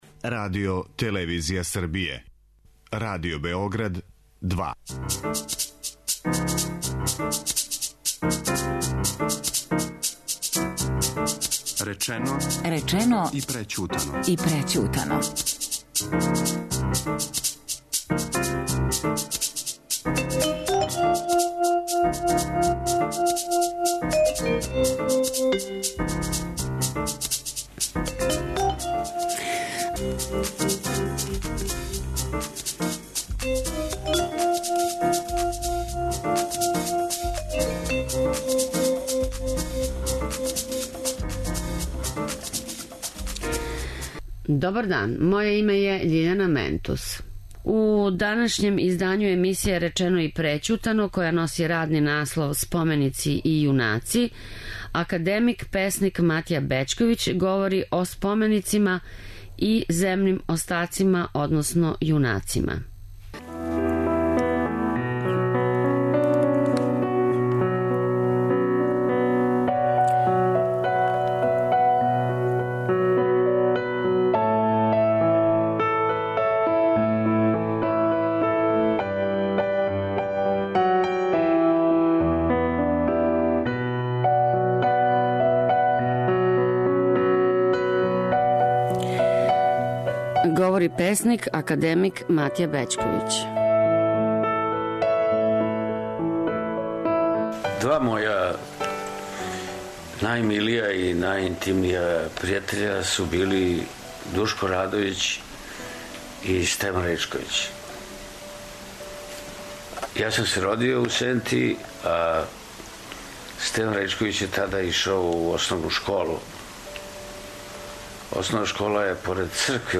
Песник Матија Бећковић говори о јунацима и њиховим земним остацима.